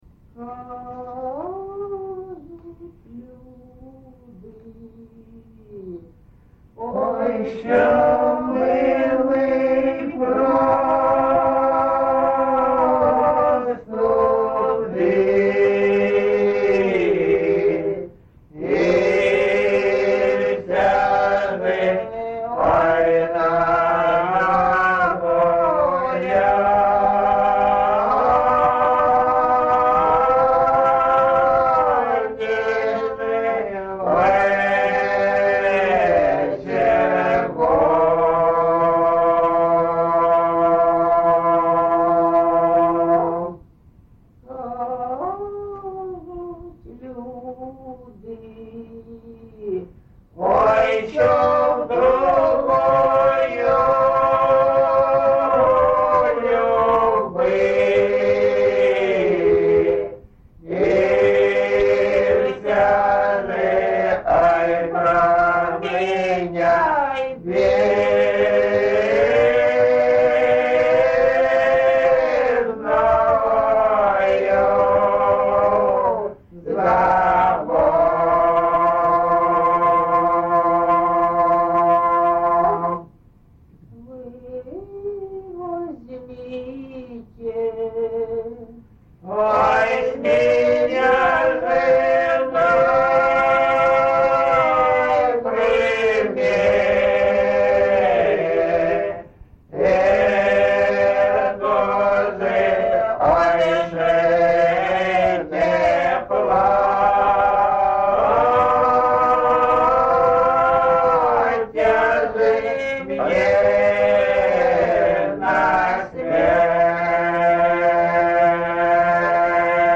GenrePersonal and Family Life, Ballad
Recording locationMarynivka, Shakhtarskyi (Horlivskyi) district, Donetsk obl., Ukraine, Sloboda Ukraine